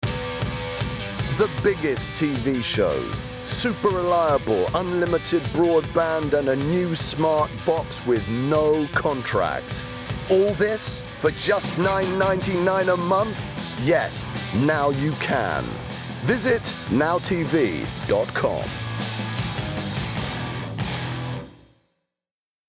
Murray has a deep, expressive and brilliantly theatrical tone to his voice.
• Male
Punchy and enthusiastic.